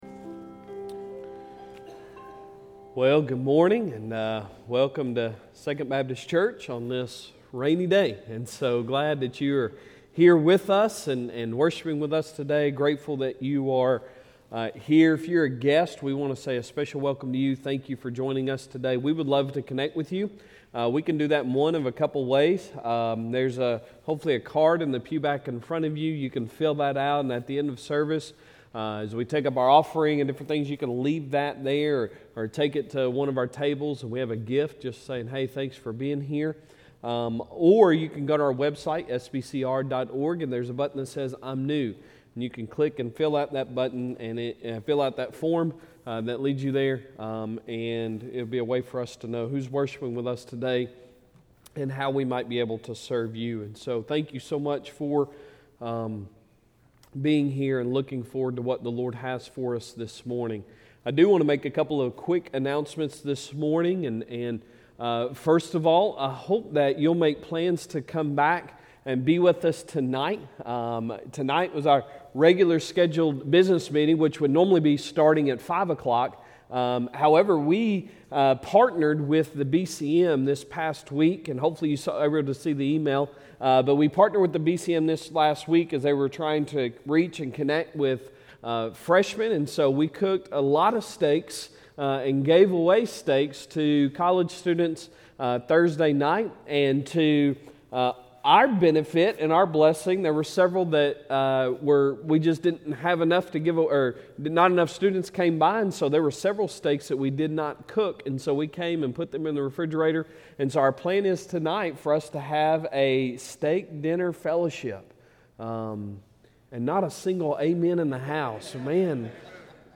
Sunday Sermon August 21, 2022